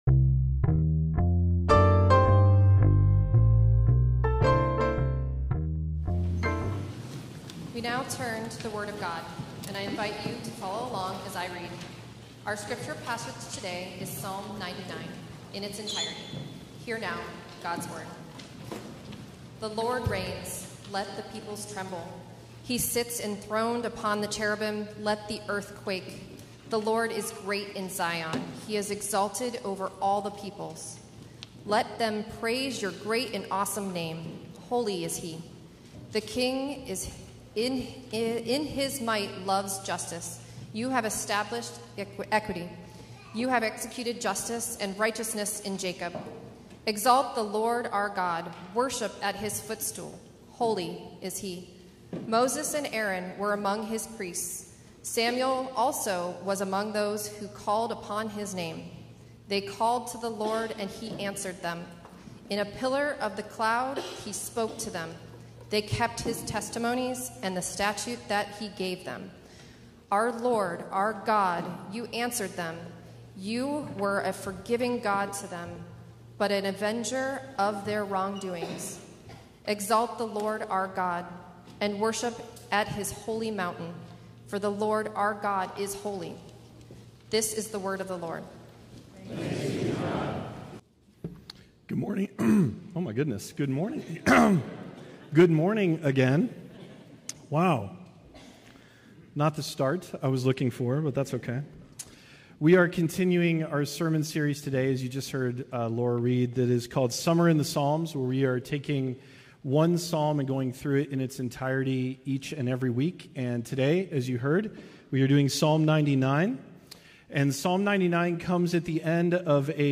Passage: Psalm 99: 1-9 Service Type: Sunday Worship